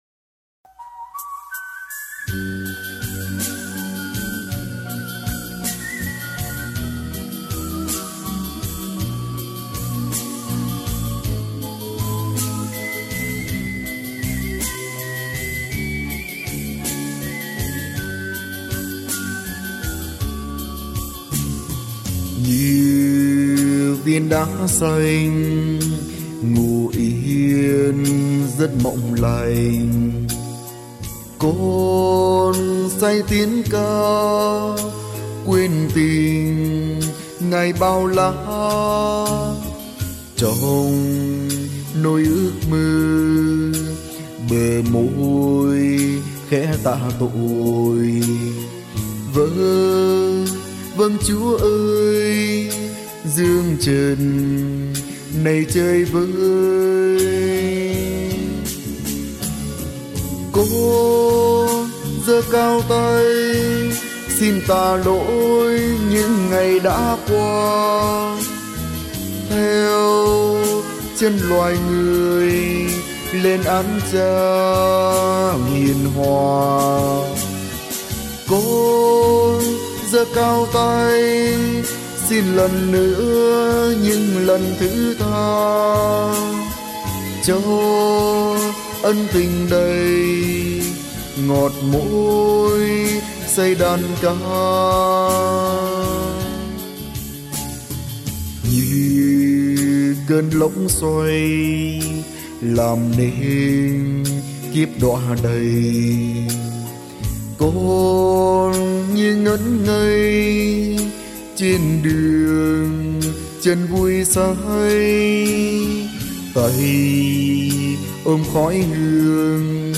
Thể loại: Thánh Ca Vào Đời